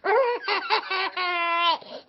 peekaboo3.ogg